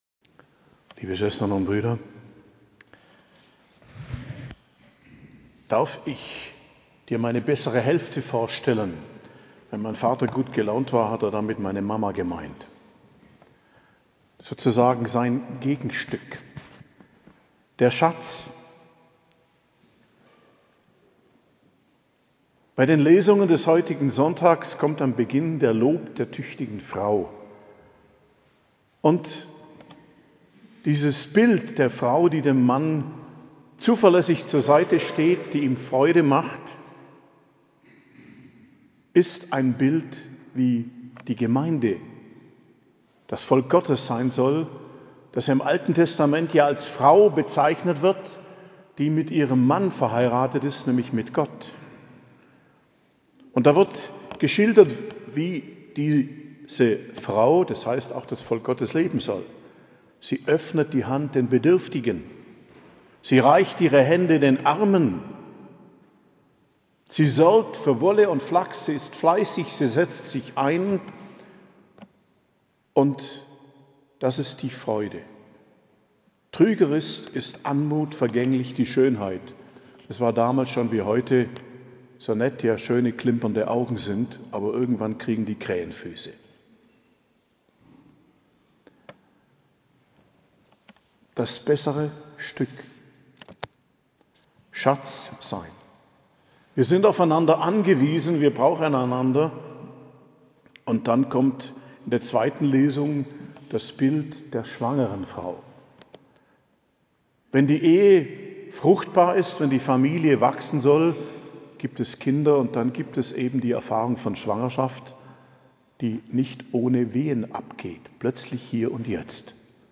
Predigt zum 33. Sonntag i.J., 19.11.2023 ~ Geistliches Zentrum Kloster Heiligkreuztal Podcast